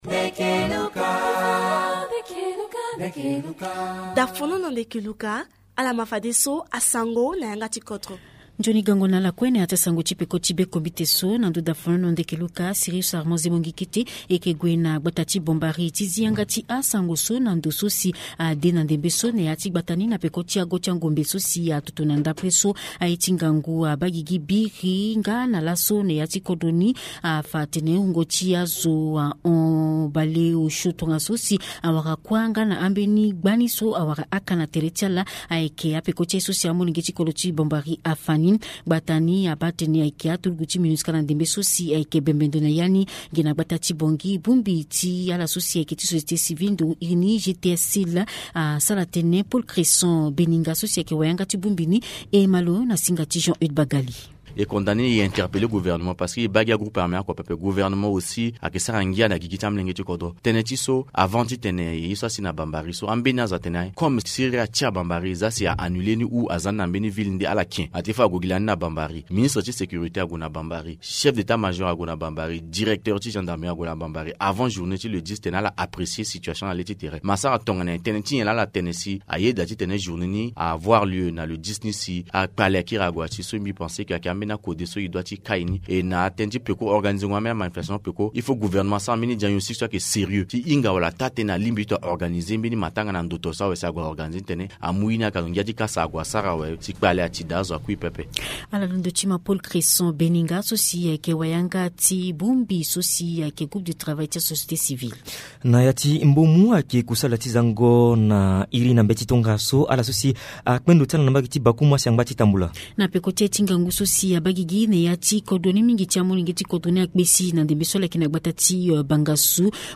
Journal Sango